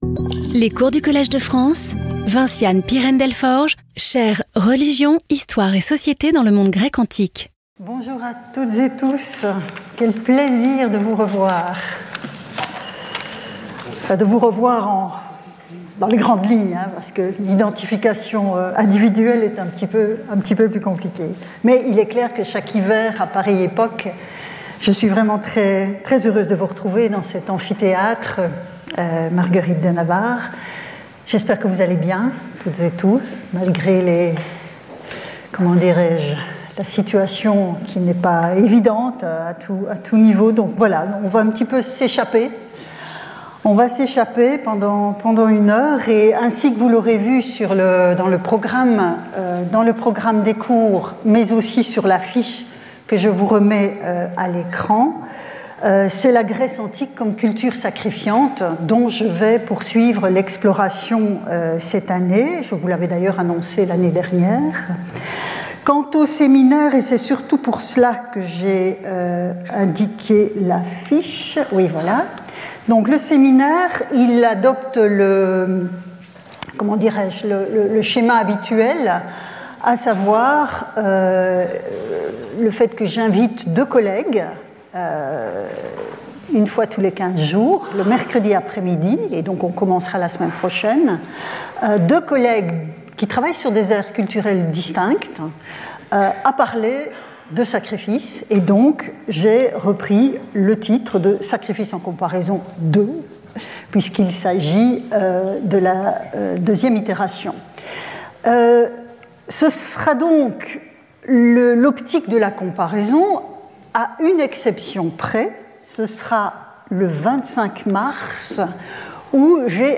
Vinciane Pirenne-Delforge Professeure du Collège de France
Cours